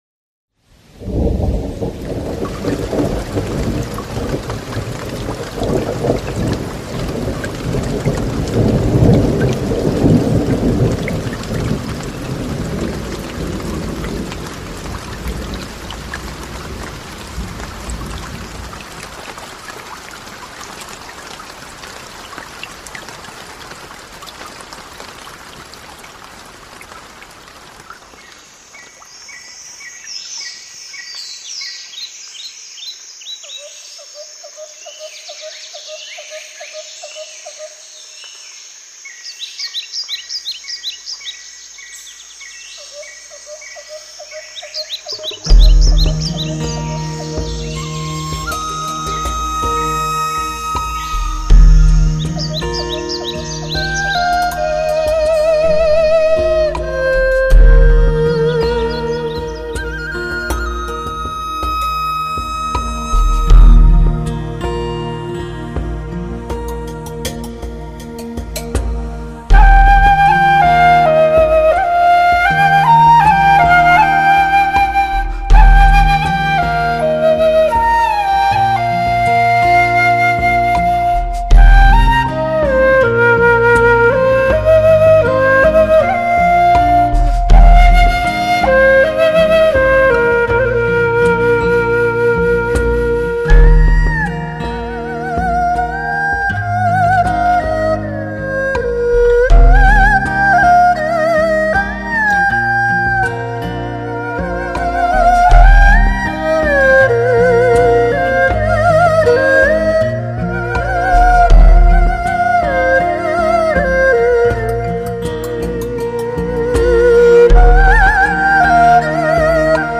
技术成就与人声的骄傲，国际新锐科技的结晶，震撼梦幻的音乐盛宴，无法想象的听觉感受，高级音响专用环绕声演示碟。